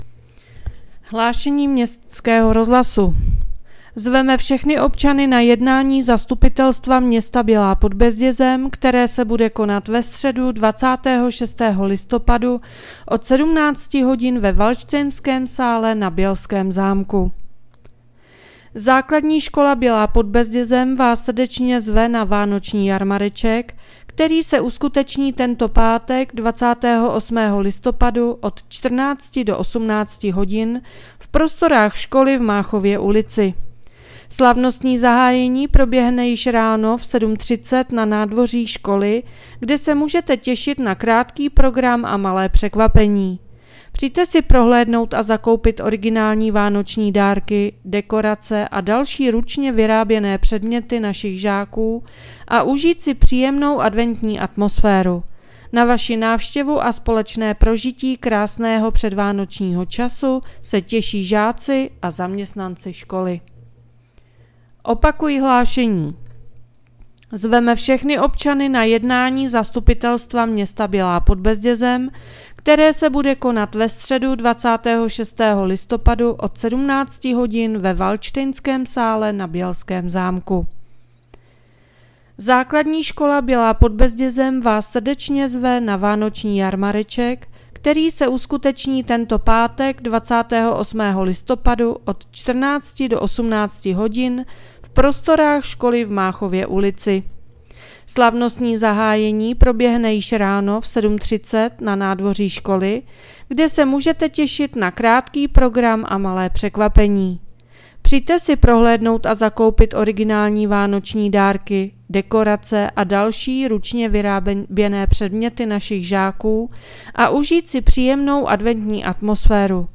Hlášení městského rozhlasu 24.11.2025